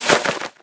bat_takeoff.ogg